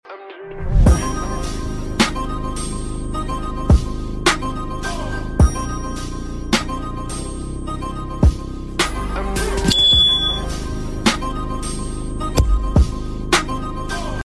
Mở Nắp Dupont âm Vang Sound Effects Free Download